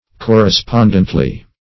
Search Result for " correspondently" : The Collaborative International Dictionary of English v.0.48: Correspondently \Cor`re*spond"ent*ly\, adv.